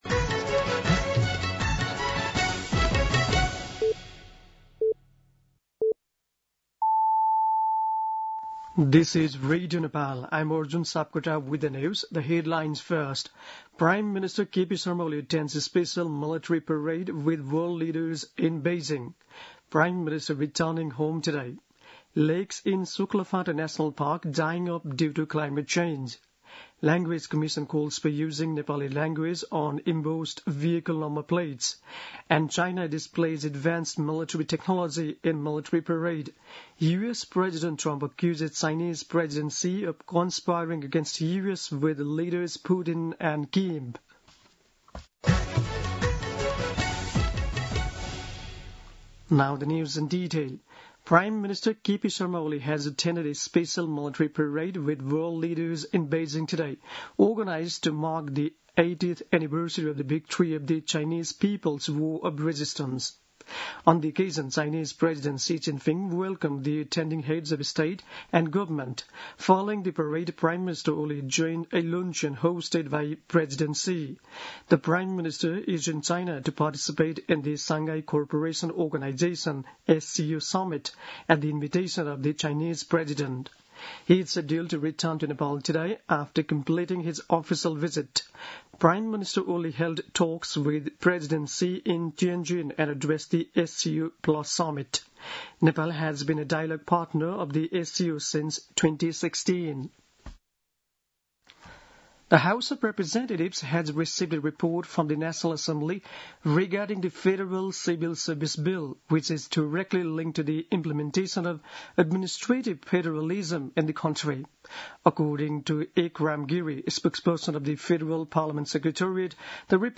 दिउँसो २ बजेको अङ्ग्रेजी समाचार : १८ भदौ , २०८२
2-pm-English-News.mp3